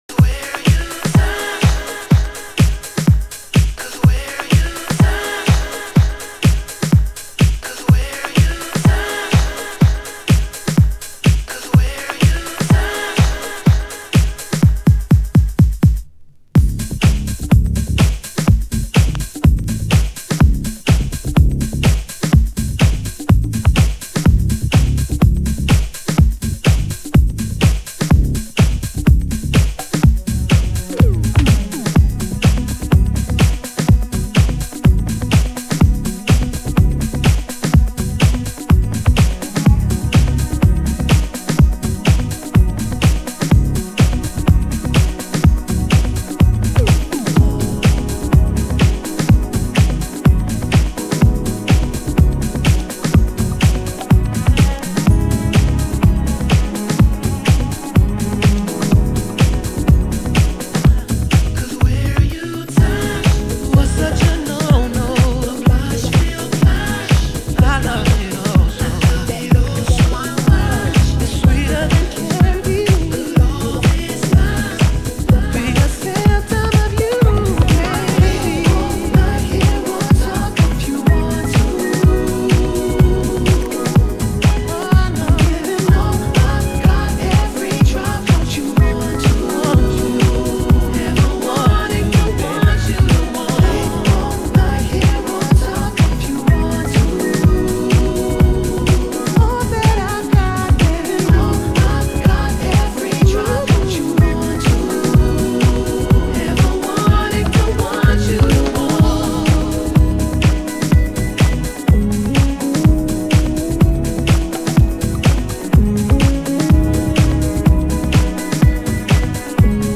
★DEEP HOUSE 歌 WHITE
盤質：軽いスレ傷/少しチリパチノイズ有